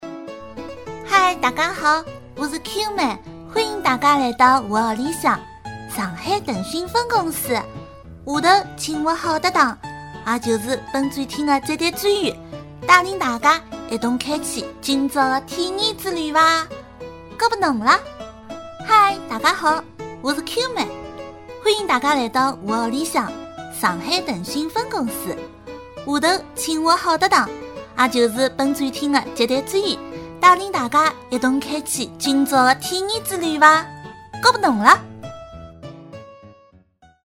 少女声